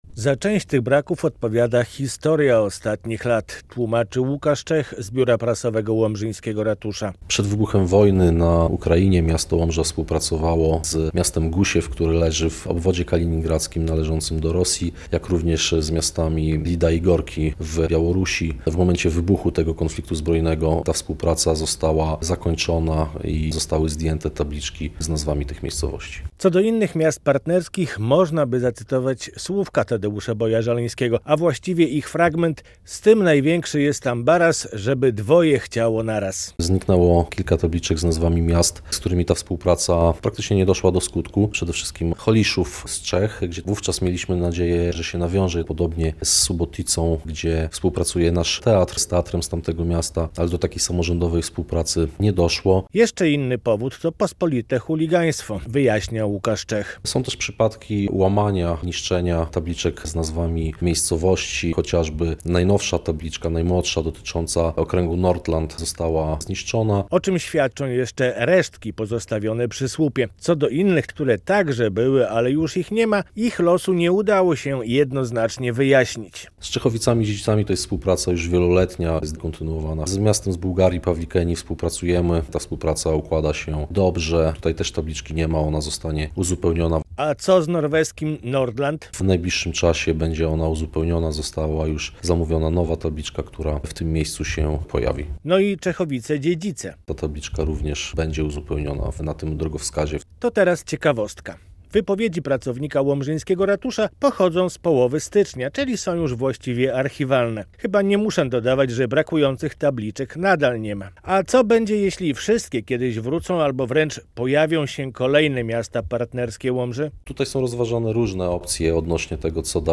W Łomży jest drogowskaz partnerstwa z nazwami zaprzyjaźnionych miast - okazuje się, że część tabliczek zniknęła - relacja